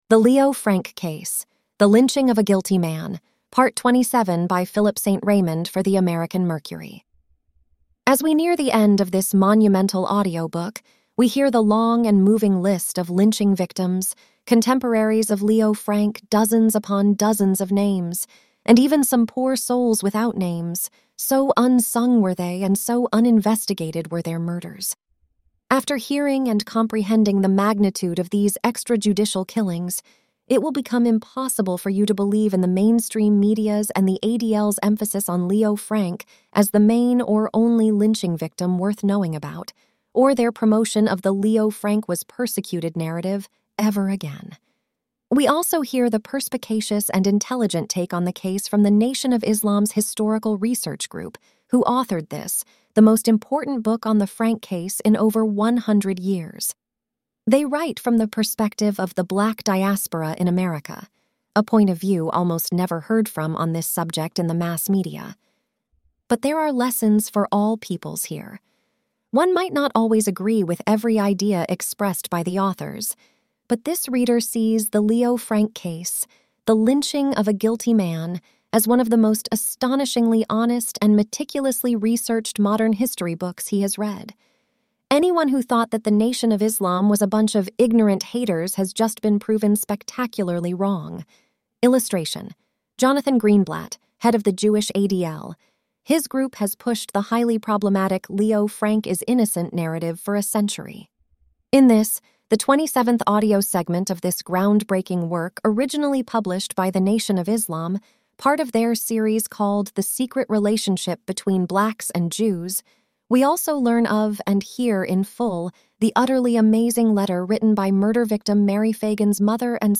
AS WE NEAR the end of this monumental audio book, we hear the long and moving list of lynching victims, contemporaries of Leo Frank — dozens upon dozens of names, and even some poor souls without names, so unsung were they and so uninvestigated were their murders.